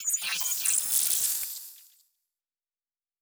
Sci-Fi Sounds / Electric / Device 9 Start.wav